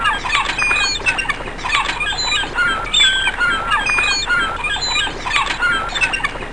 longgull.mp3